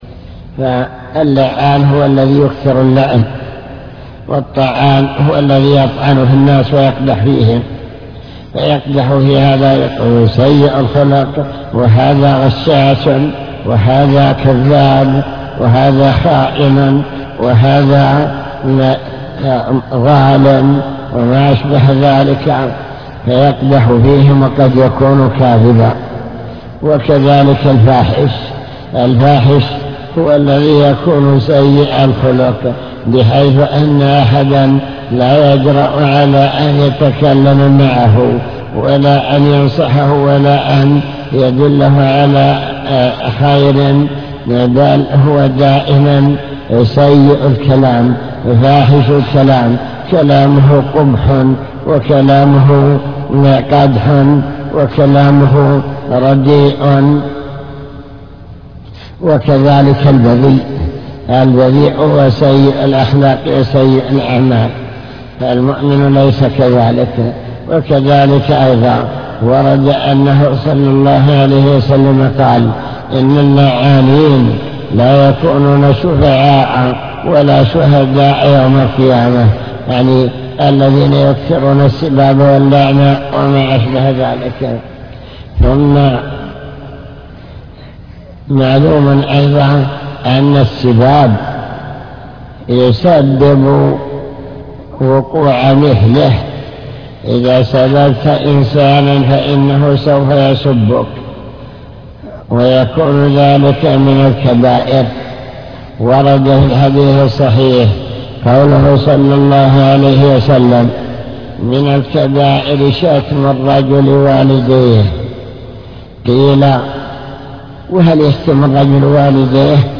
المكتبة الصوتية  تسجيلات - كتب  شرح كتاب بهجة قلوب الأبرار لابن السعدي شرح حديث المسلم من سلم المسلمون